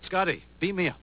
If your computer is set up properly to play sounds from Web files, you should hear a short sound clip.